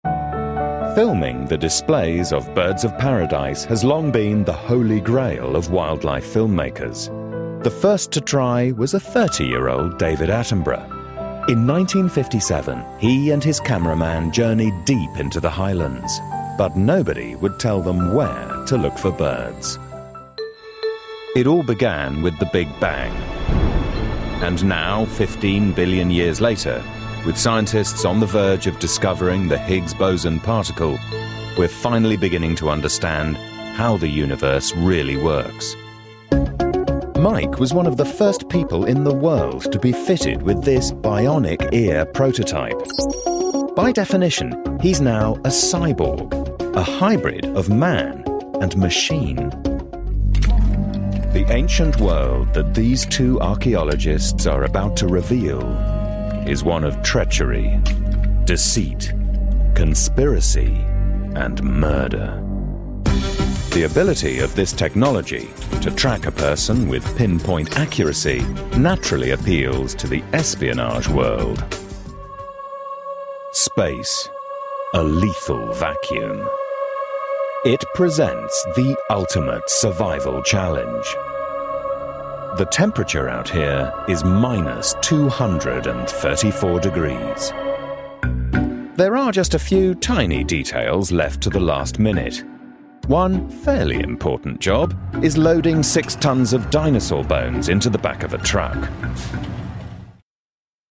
BBC British MVO. A voice people warm to, believe in and laugh with.
Sprechprobe: Sonstiges (Muttersprache):
From deep and dark to wry humour. Intelligent voiceover and skilled narrator.